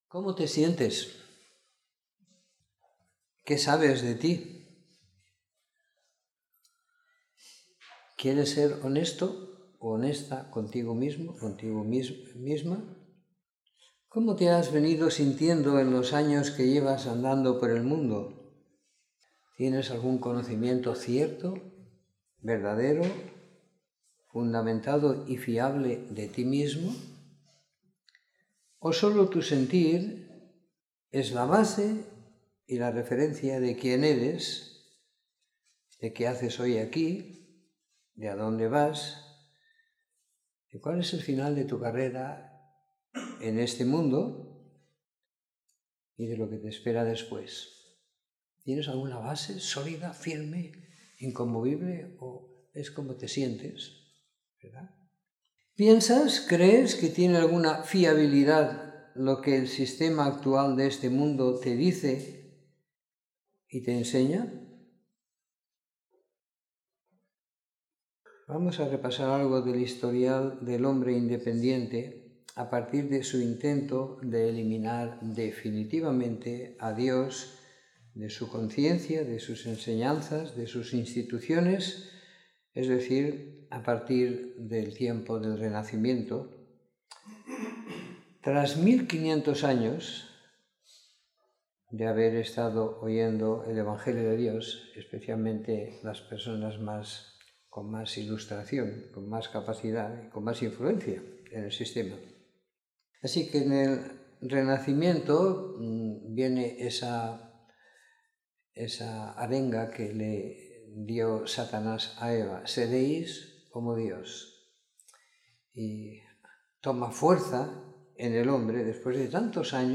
Domingo por la Mañana . 05 de Noviembre de 2017